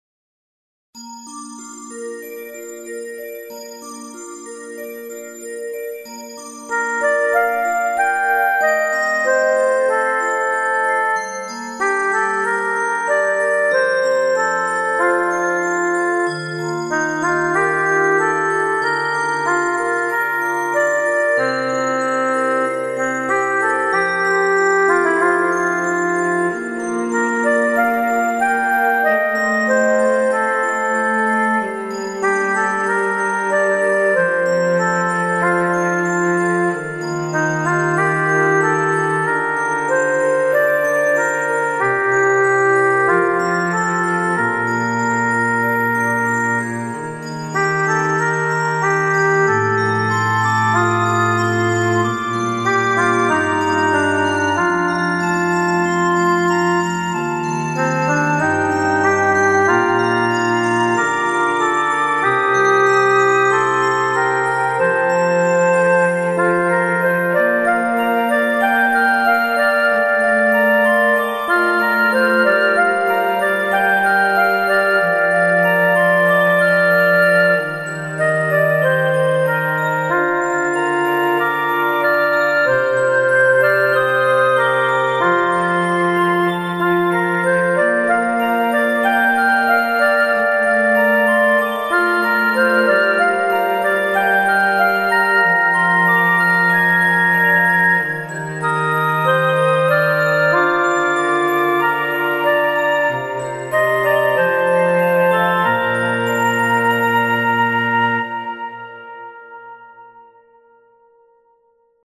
ファンタジーポップロング明るい穏やか
BGM